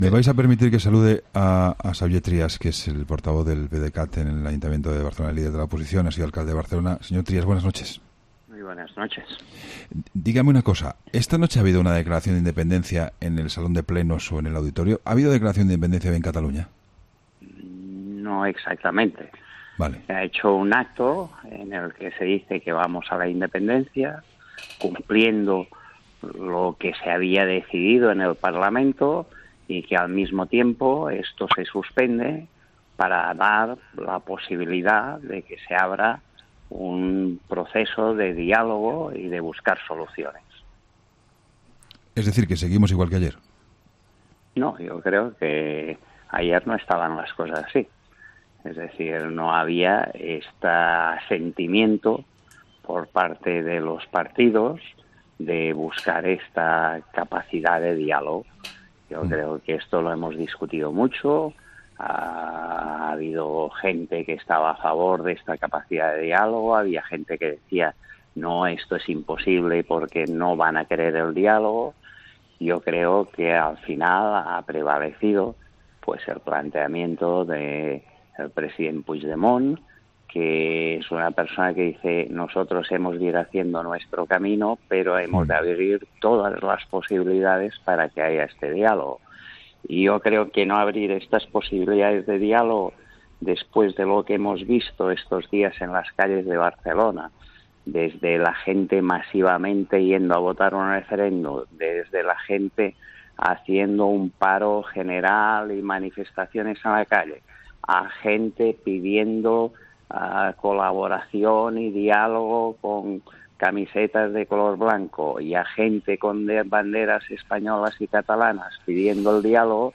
Entrevistas en La Linterna
Xavier Trías, portavoz del PDeCAT en el Ayuntamiento de Barcelona, en 'La Linterna'